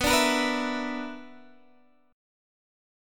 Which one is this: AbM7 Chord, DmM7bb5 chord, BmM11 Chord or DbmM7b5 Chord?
BmM11 Chord